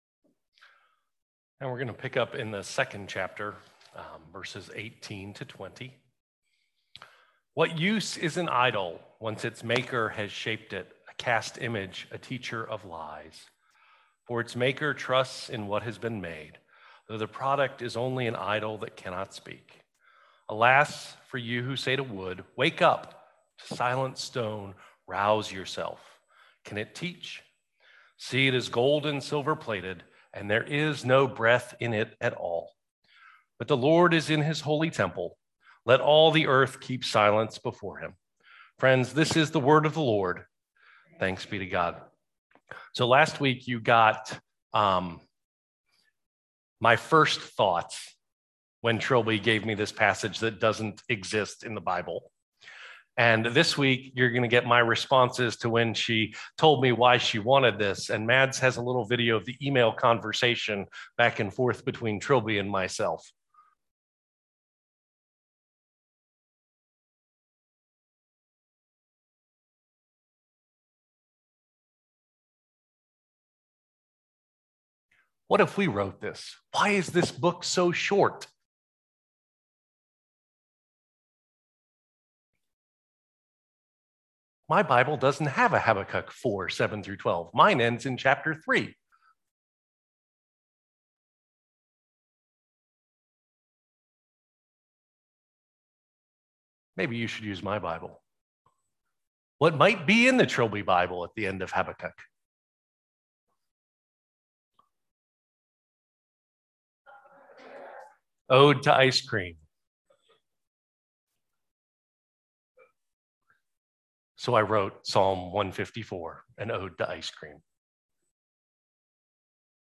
Worship 2021